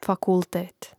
fakùltēt fakultet